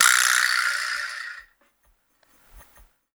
129-FX1.wav